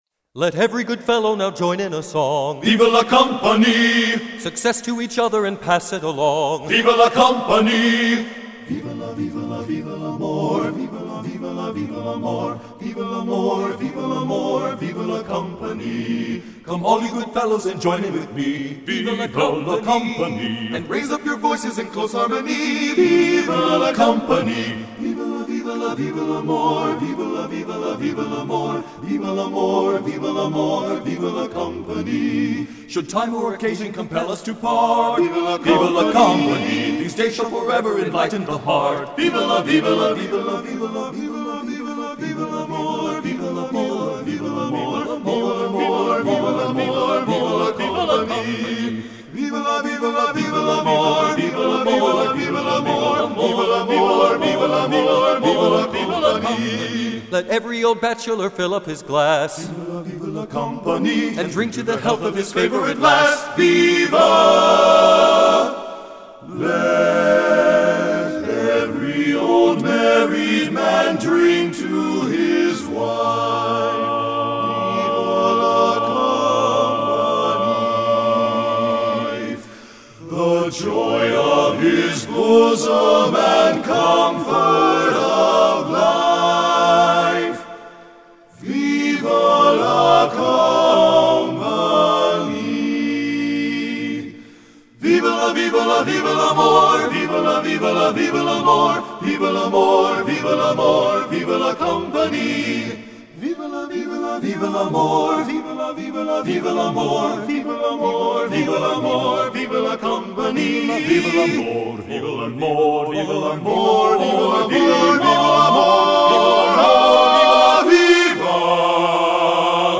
lawson gould choral